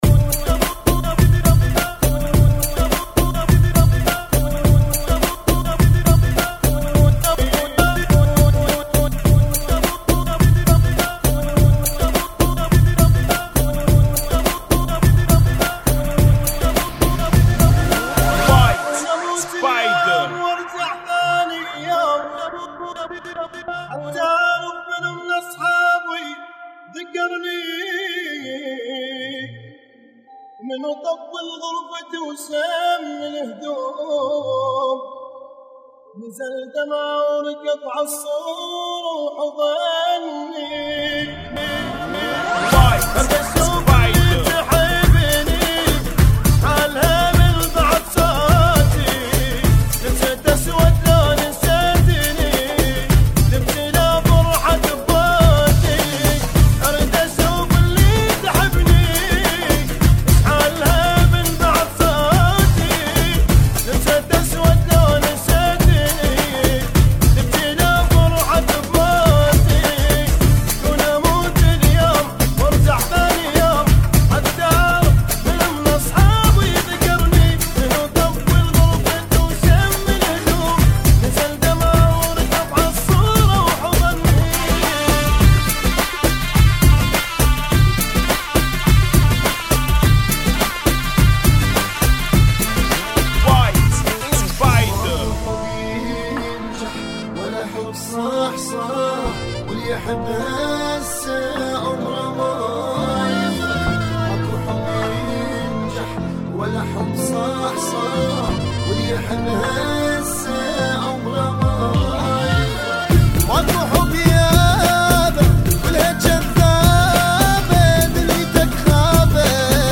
Funky [ 104 Bpm ]